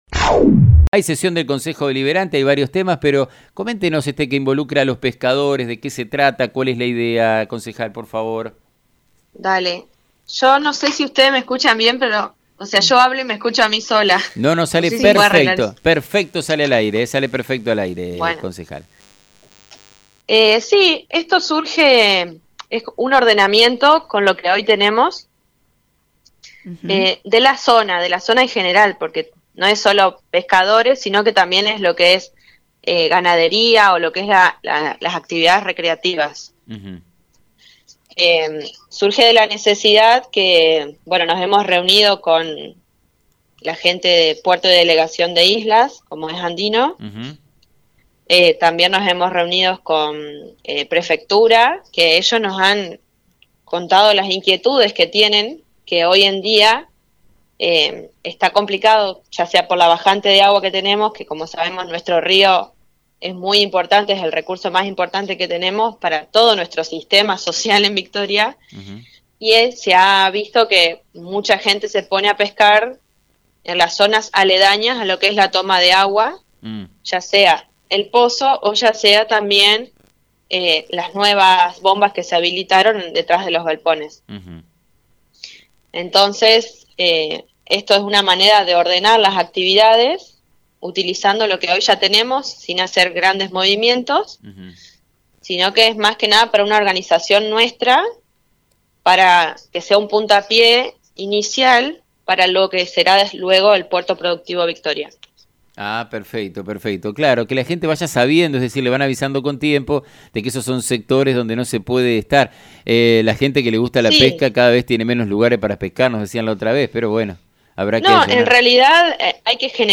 La concejal Emilia Forlese explicó en diálogo radial con FM 90.3 que este proyecto surge de la necesidad de ordenar y distribuir las actividades, mediante una organización interna, para que funcione todo correctamente, y actúe como puntapié inicial en la formación del Puerto productivo Victoria.